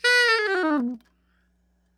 SOPRANO FALL
SOP SHRT A#4.wav